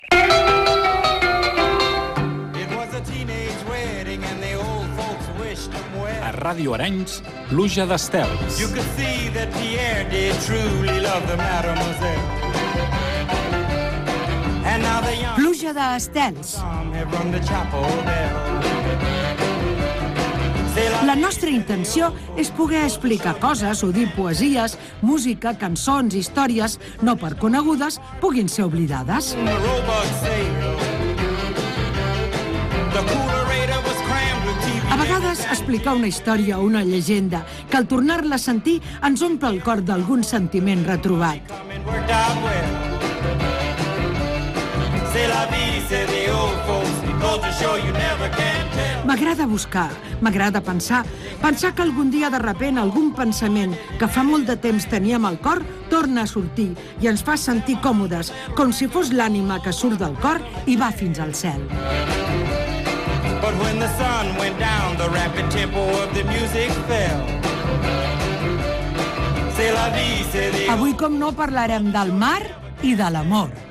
Careta i inici del programa i anunci del tema: el mar i l'amor.
Entreteniment
FM